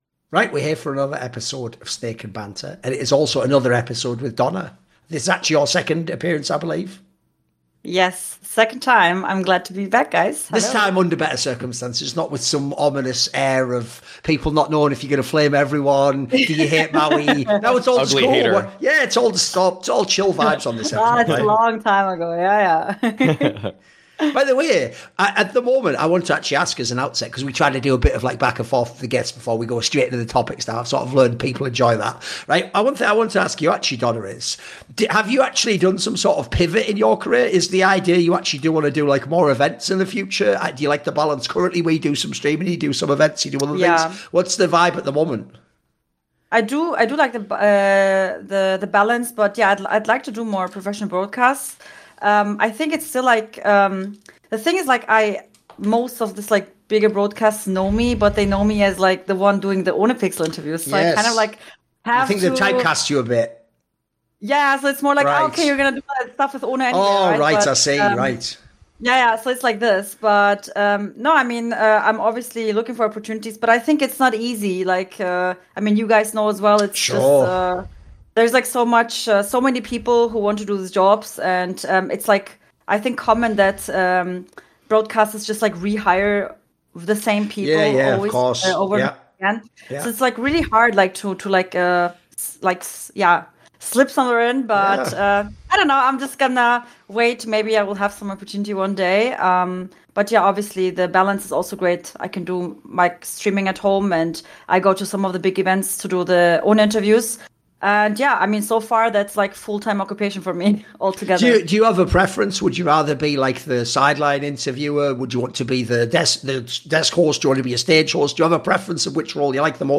Snake & Banter is a Counter-Strike talk show